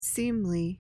seemly.mp3